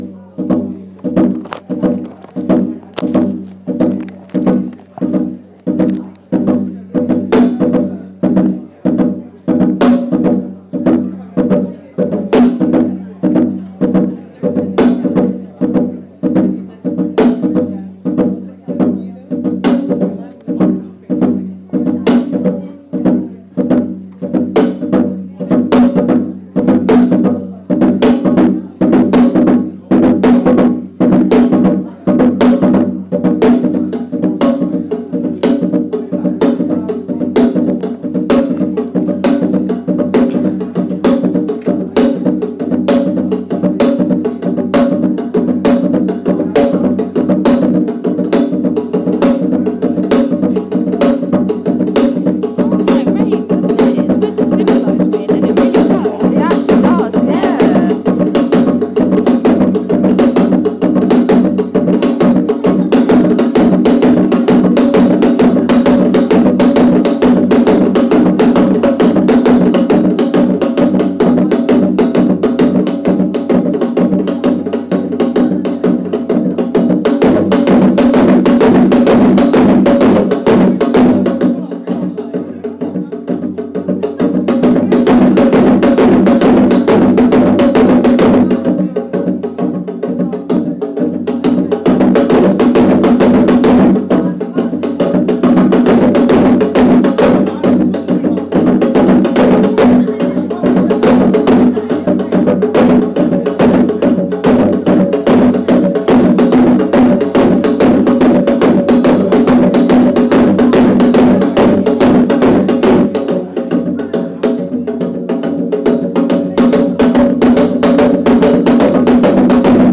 Demo of Korean drumming (Samulnori) at soas